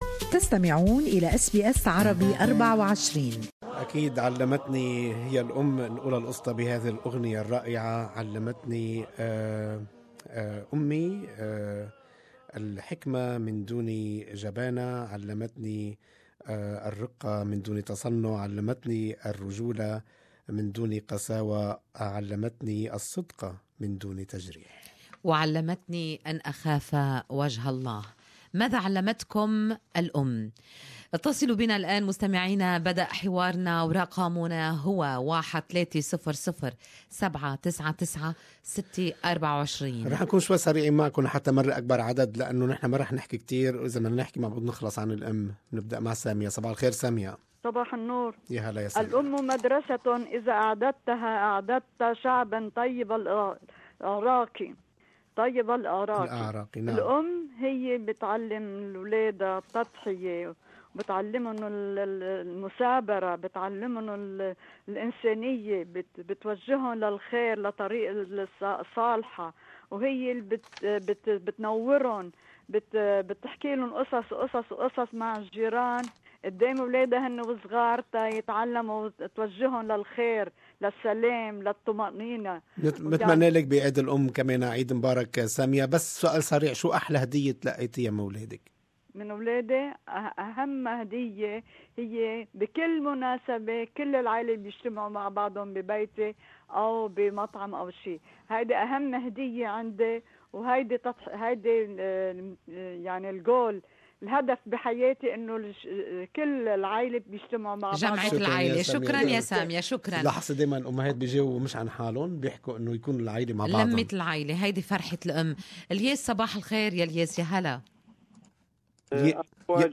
On the morning show, Good Morning Australia, listeners share their messages to their mums. Some sang, some recited poems and others poured their hearts out.